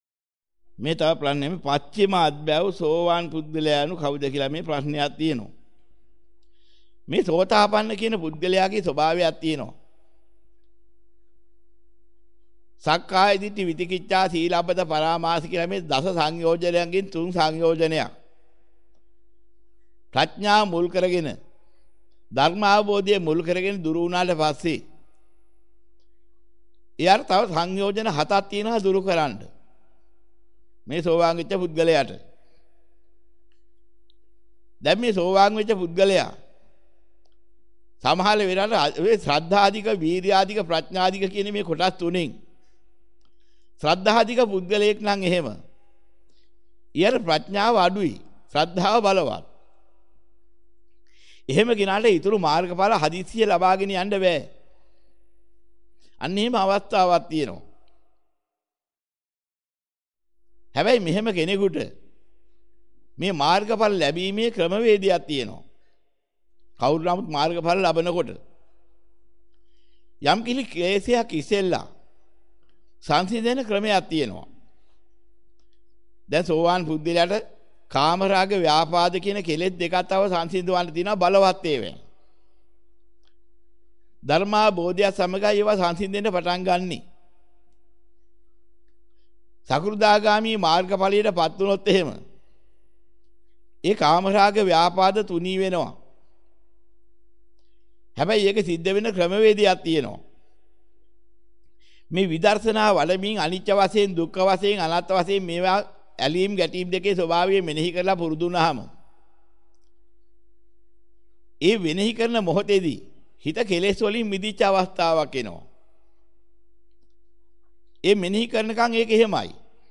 වෙනත් බ්‍රව්සරයක් භාවිතා කරන්නැයි යෝජනා කර සිටිමු 29:53 10 fast_rewind 10 fast_forward share බෙදාගන්න මෙම දේශනය පසුව සවන් දීමට අවැසි නම් මෙතැනින් බාගත කරන්න  (18 MB)